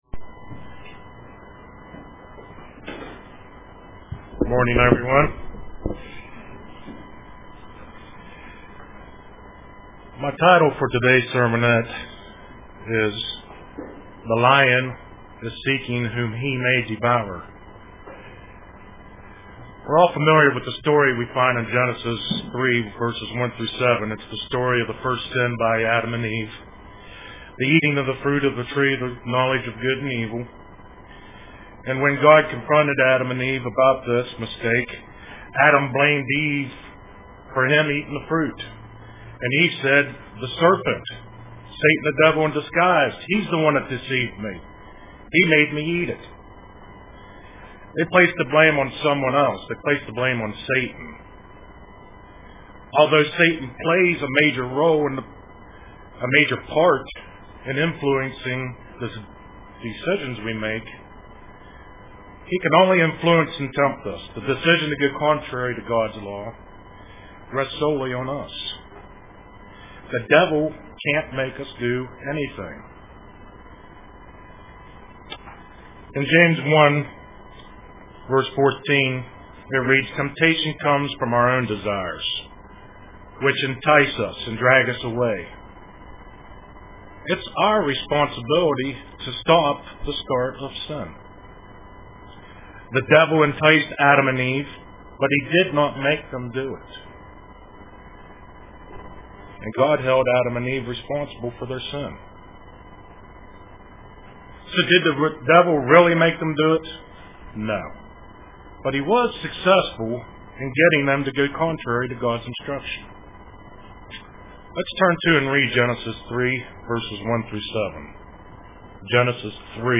Print The Lion is Seeking Whom He may Devour UCG Sermon Studying the bible?